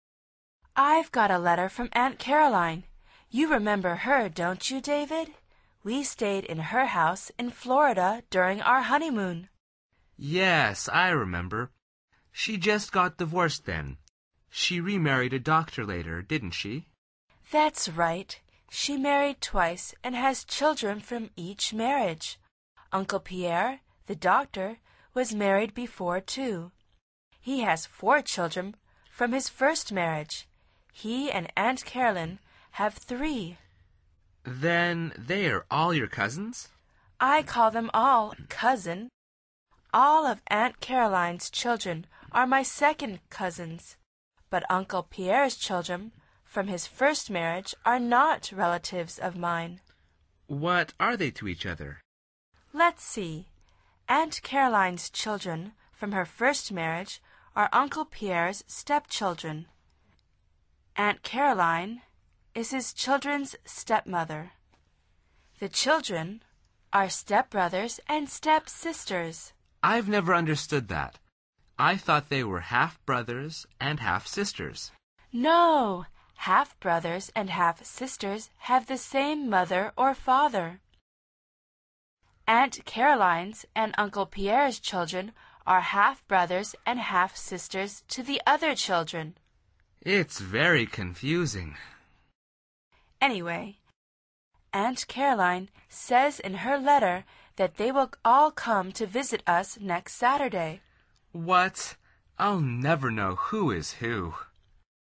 Pulsa las flechas de reproducción para escuchar el segundo diálogo de esta lección. Al final repite el diálogo en voz alta tratando de imitar la entonación de los locutores.